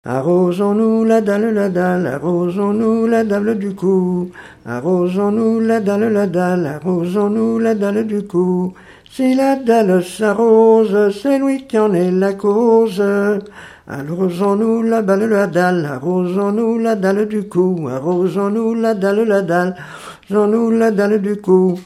circonstance : bachique
Genre brève
Enquête Arexcpo en Vendée
Pièce musicale inédite